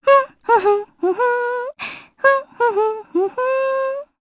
Mediana-Vox_Hum_jp.wav